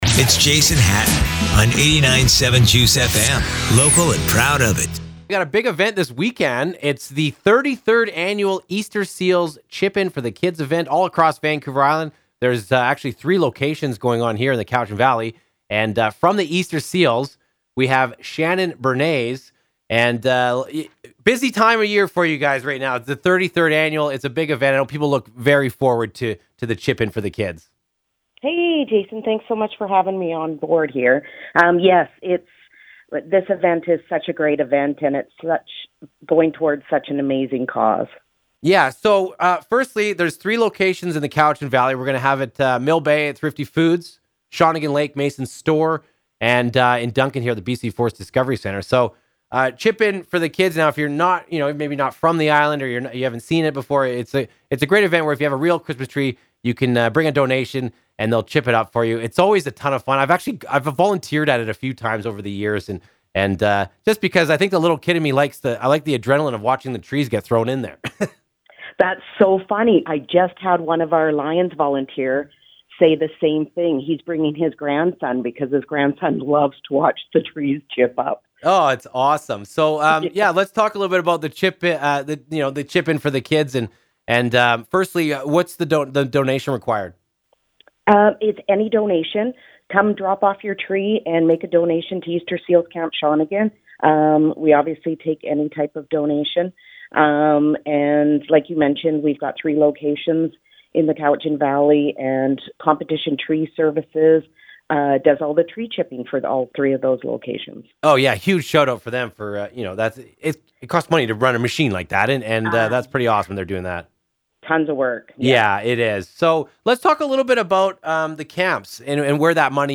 Easter-Seals-Chip-in-for-the-kids-interview-2023.mp3